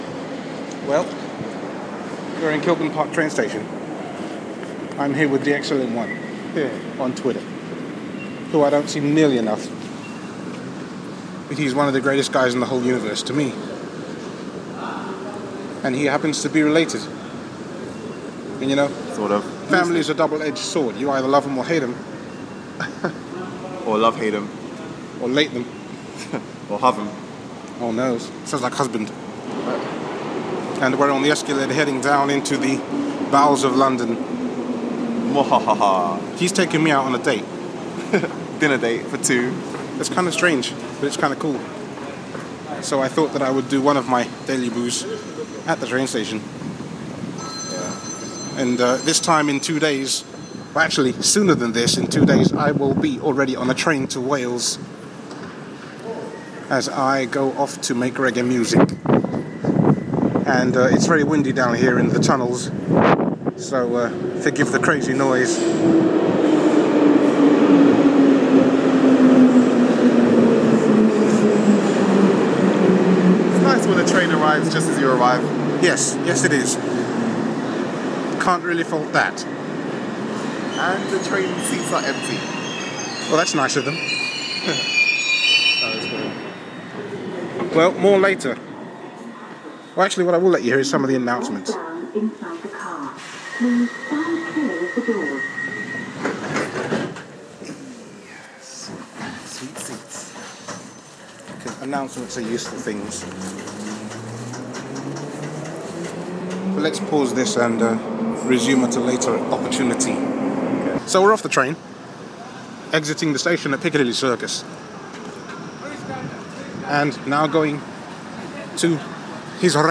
Many many things, including a guy singing into a... Traffic cone?